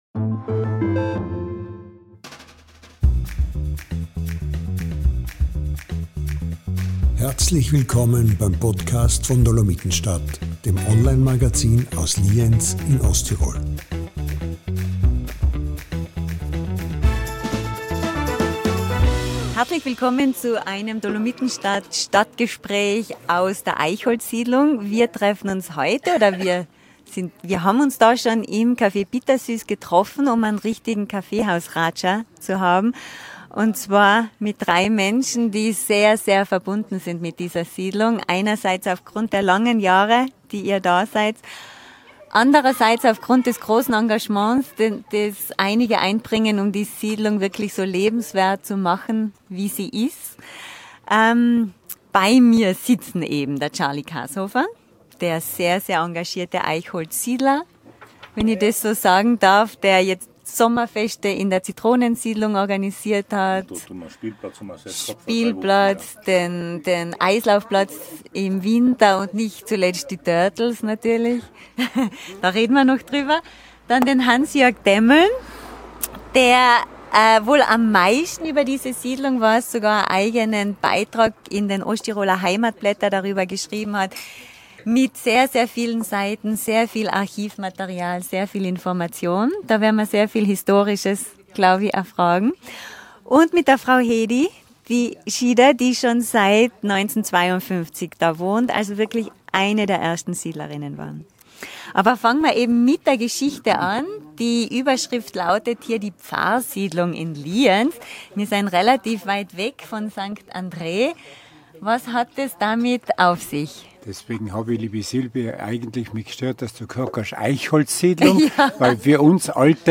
Das Café „Bittersüß“ in Lienz schließt eigentlich um 18:00 Uhr. Heute bleibt es für uns ein wenig länger offen, denn wir treffen uns dort, um in Ruhe über einen der größten Stadtteile von Lienz zu sprechen: die Eichholzsiedlung - und über sie gibt es tatsächlich sehr viel zu erzählen....